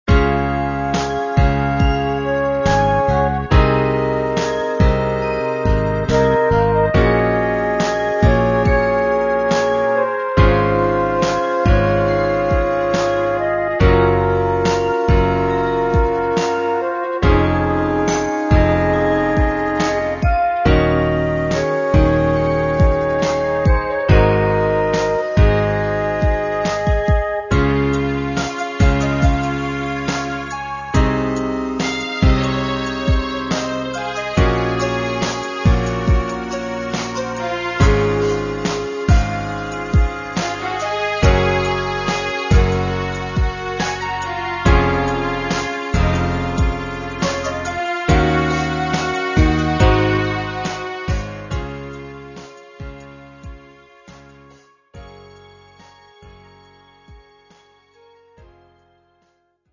モノラルまで音質を落としています。
またイントロから１分間のみになりますがご了承ください。
恋愛もののゲームで使えそうな曲作ってみました。